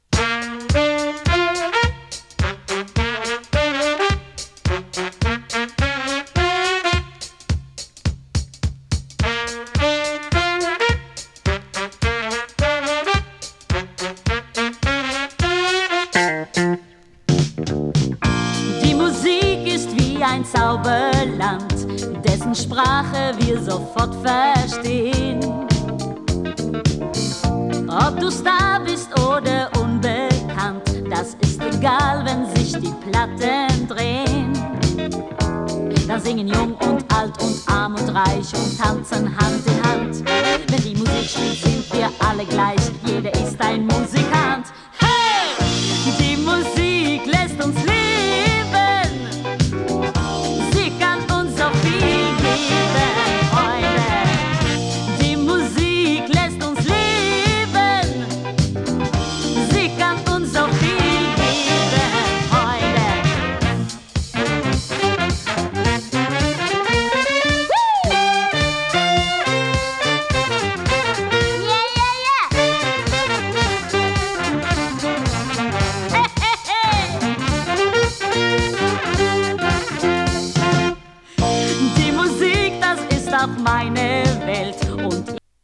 ノルウェー出身のボーカリストによるドイツ語録音。